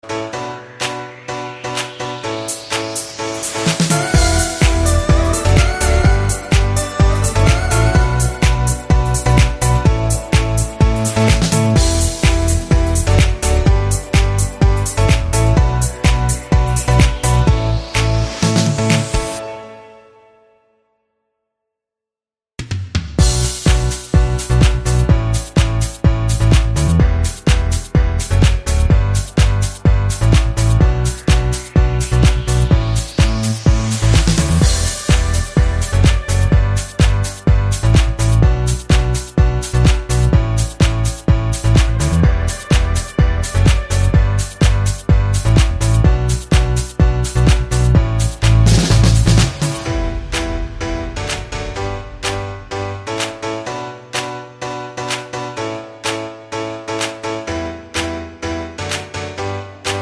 no Backing Vocals Dance 3:01 Buy £1.50